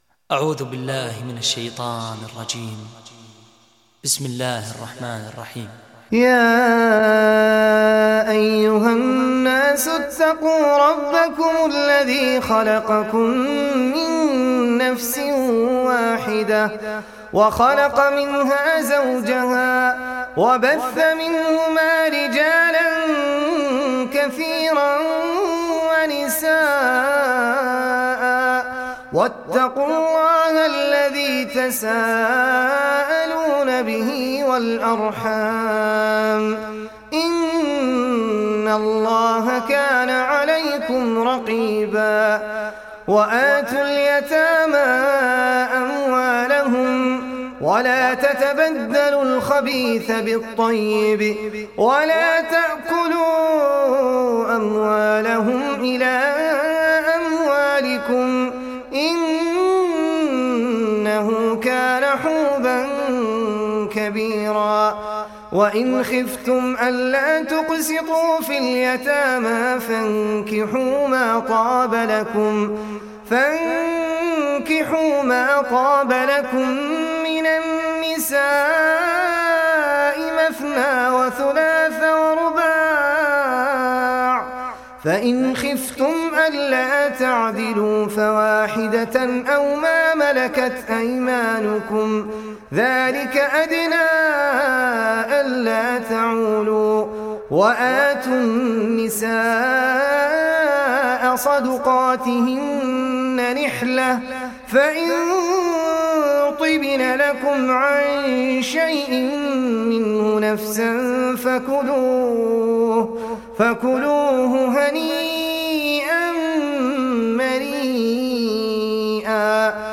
تحميل سورة النساء mp3 بصوت أحمد العجمي برواية حفص عن عاصم, تحميل استماع القرآن الكريم على الجوال mp3 كاملا بروابط مباشرة وسريعة